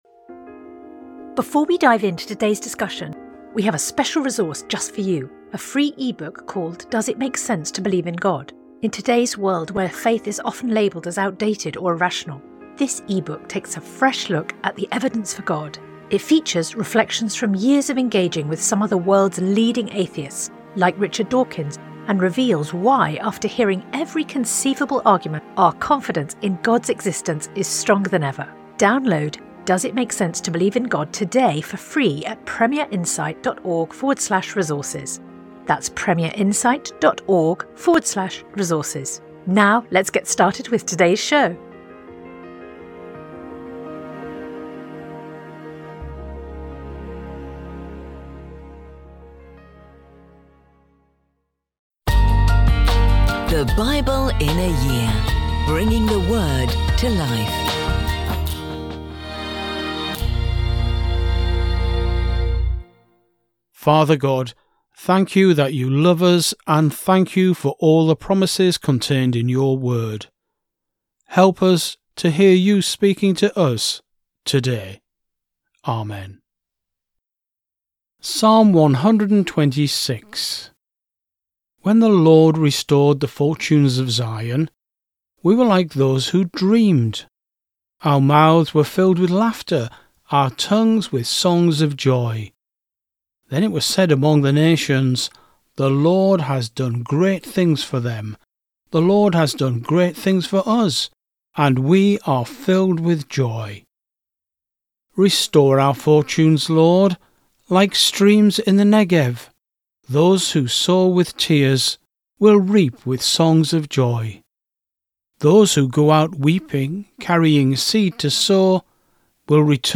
Every day throughout the year we'll be bringing you an audio scripture reading from the Old and New Testament.